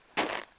snowFootstep04.wav